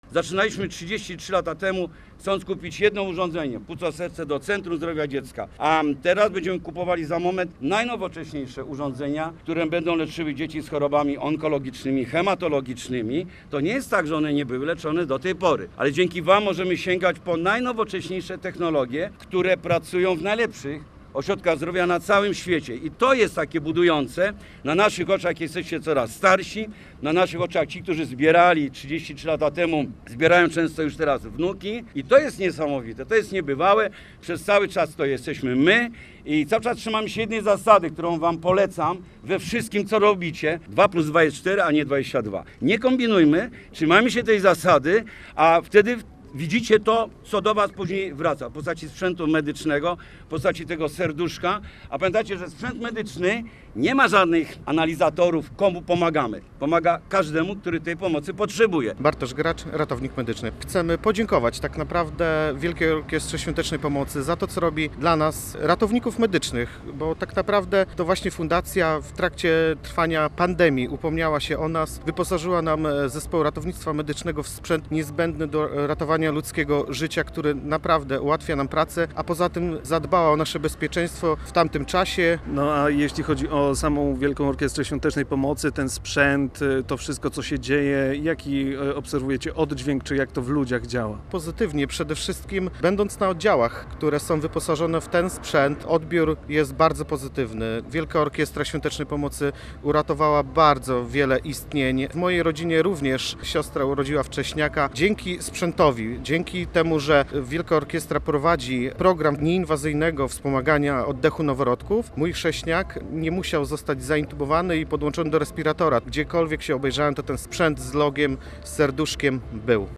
Więcej o wydarzeniu w materiale naszego reportera: https